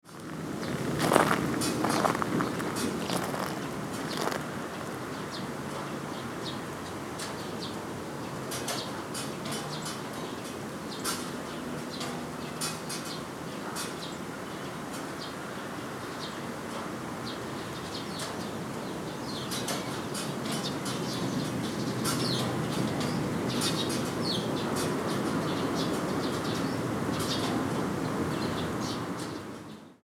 この曲ではフィールドレコーディングした音（サウンドスケープ）は使用せず、その際の情景や情感を楽音で表現しています。